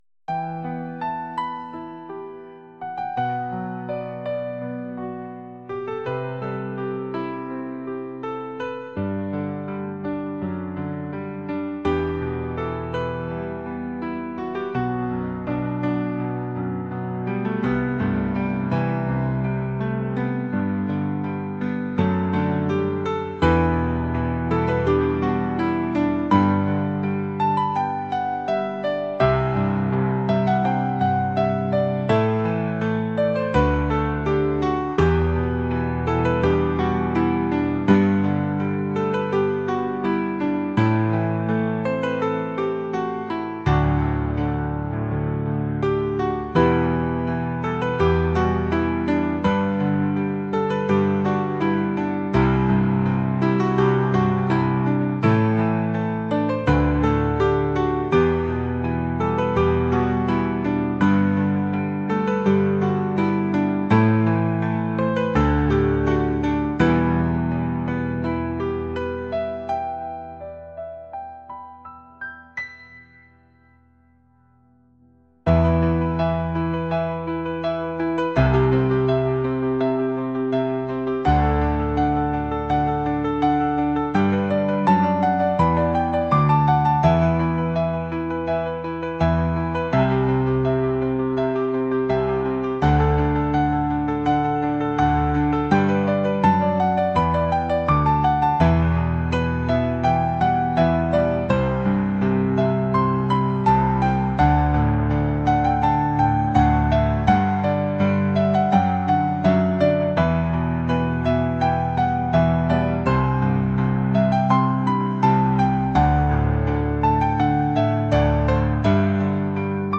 rock | indie | acoustic